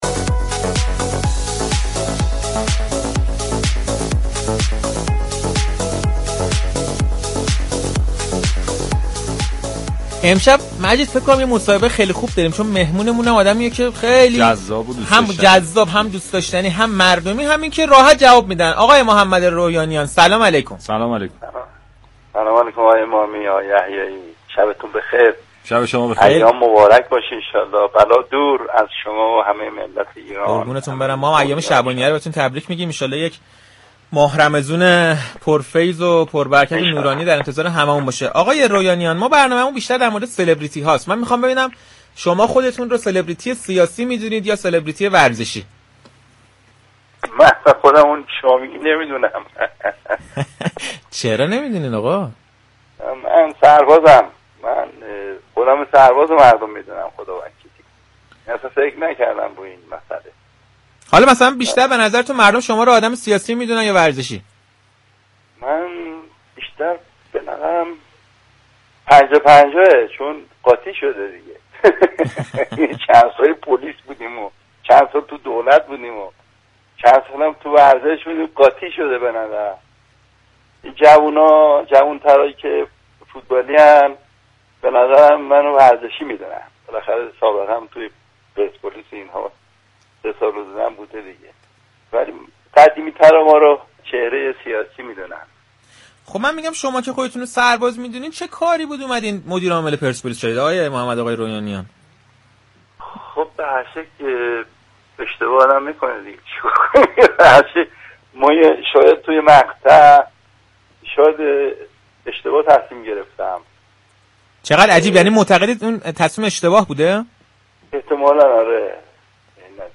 سردار محمد رویانیان در پشت‌صحنه‌ی رادیو تهران، حضورش در عرصه‌ی ورزش و مدیرعاملی باشگاه پرسپولیس را از اشتباهات زندگی‌اش در فعالیت‌های اجتماعی خود دانست.